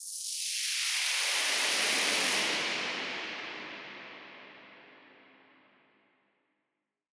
Index of /musicradar/shimmer-and-sparkle-samples/Filtered Noise Hits
SaS_NoiseFilterA-03.wav